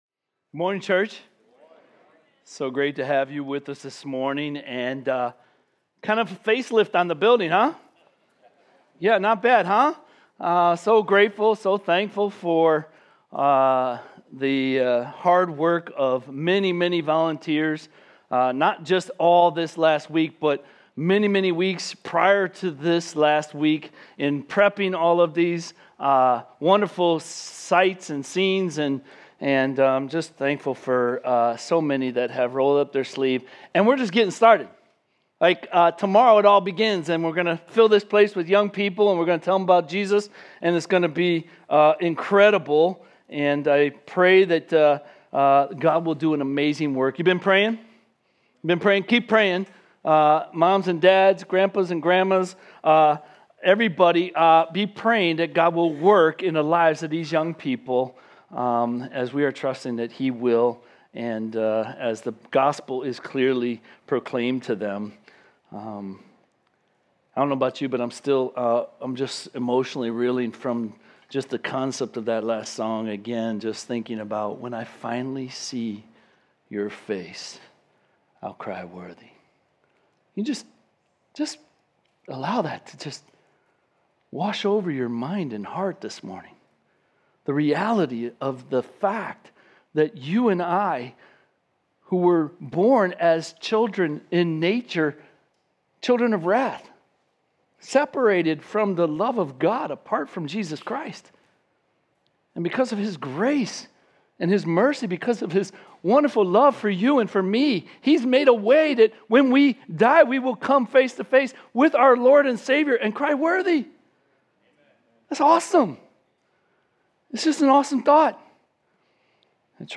Home Sermons Cornerstone